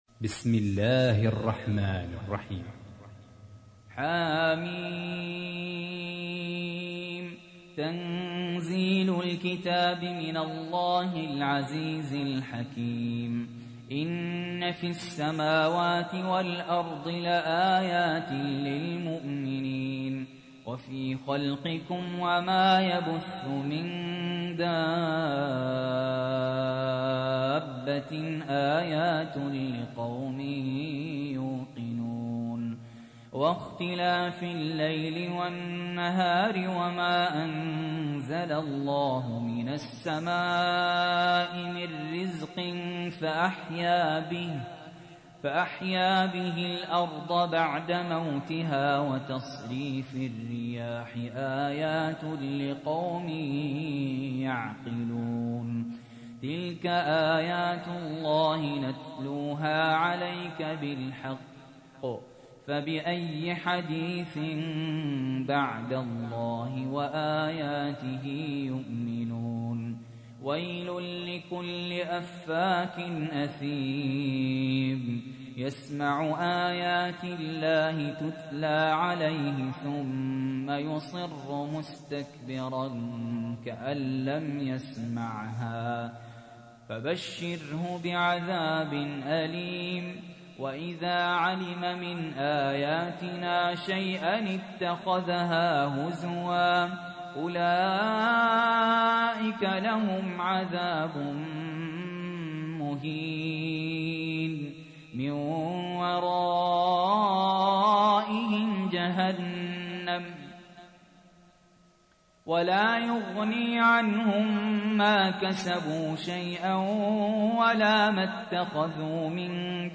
تحميل : 45. سورة الجاثية / القارئ سهل ياسين / القرآن الكريم / موقع يا حسين